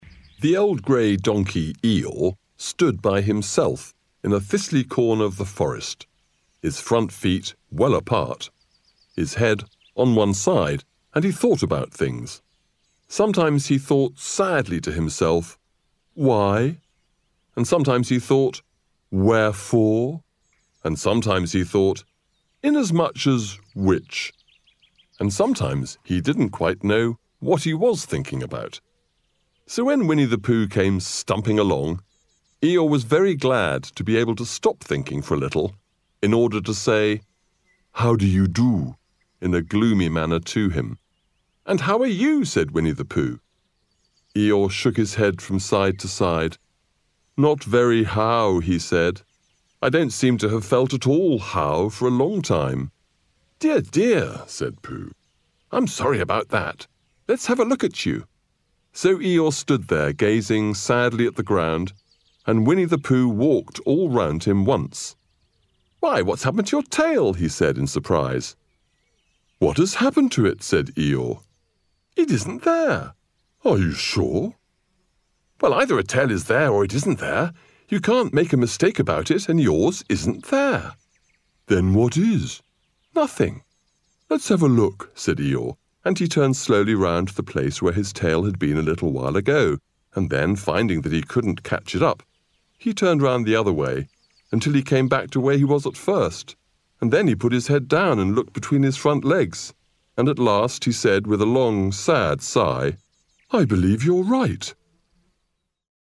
CHILDRENS BOOK: READING